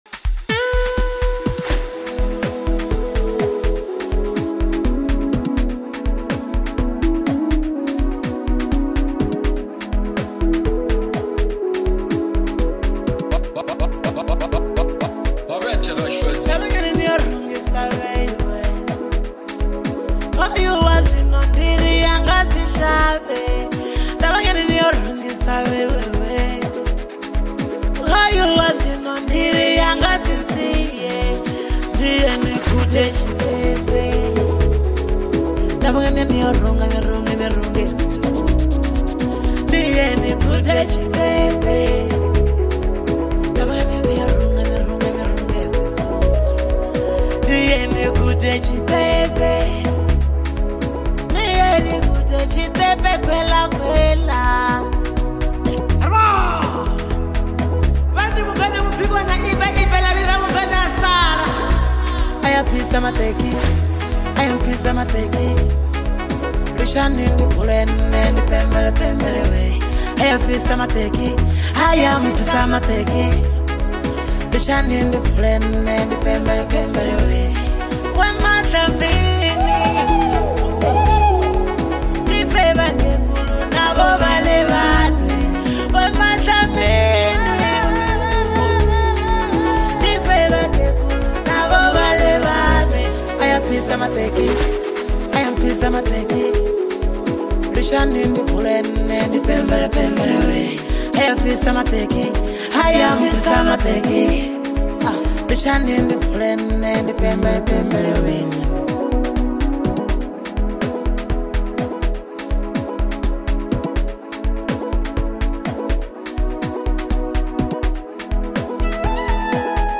Amapiano and Afrobeat